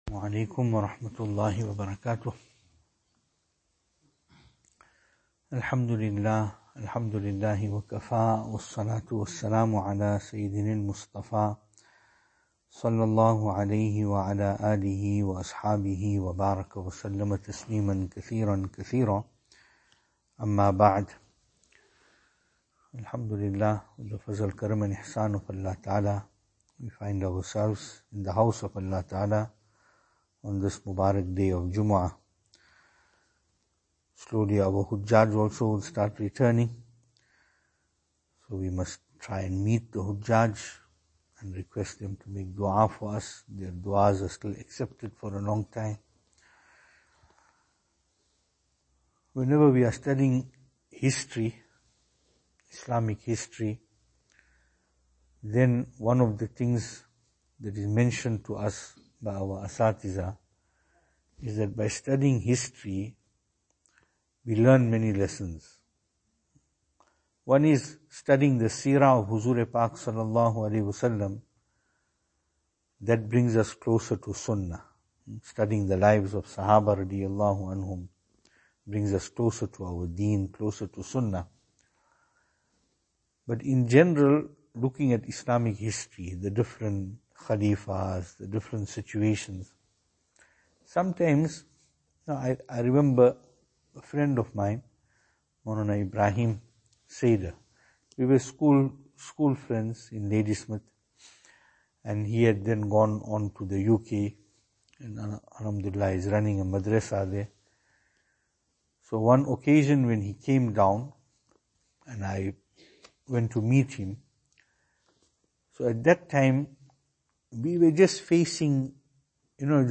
2022-07-15 Challenges Faced By Hadhrat Mujaddid Alfe Thani Rahmatullahi Alaih Venue: Albert Falls , Madressa Isha'atul Haq Service Type: Jumu'ah « To Plan One’s Self Regarding That Which Is Within Our Means Is Commendable.